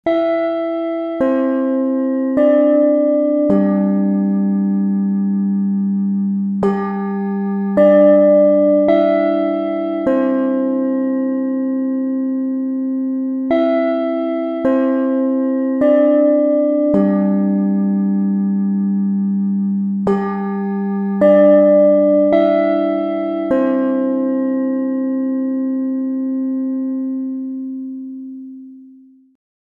空調の室外機